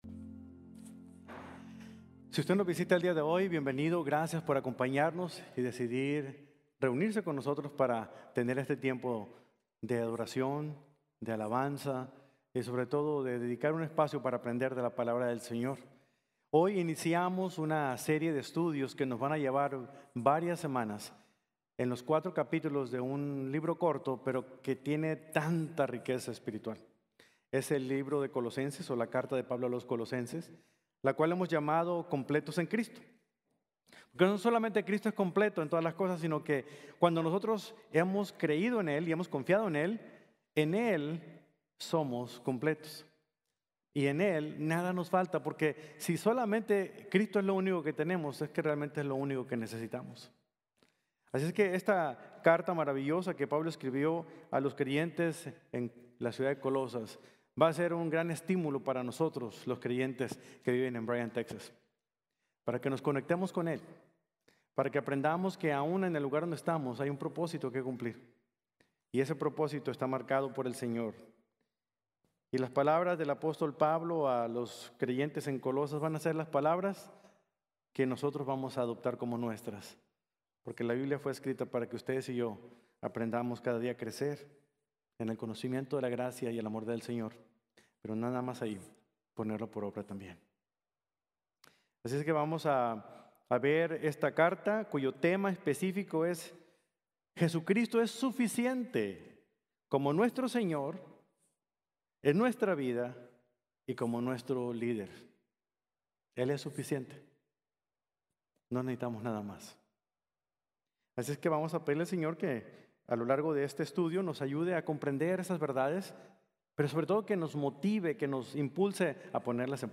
Completo en Cristo | Sermón | Iglesia Bíblica de la Gracia